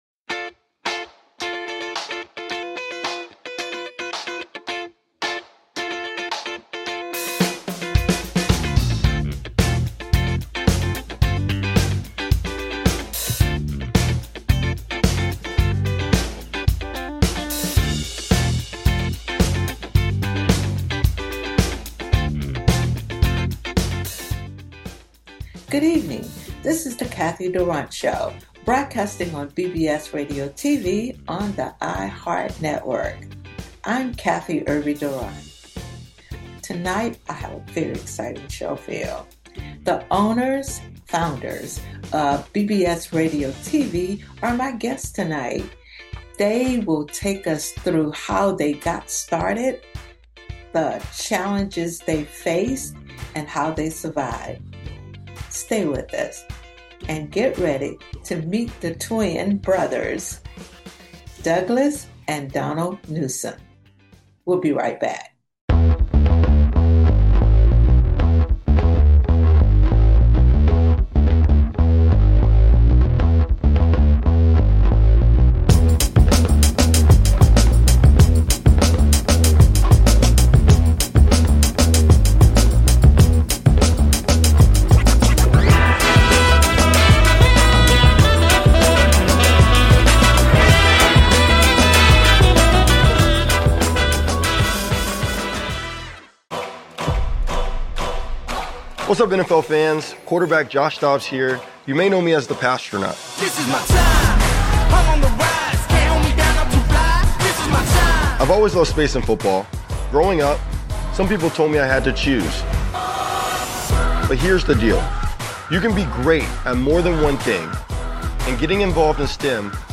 Headlined Show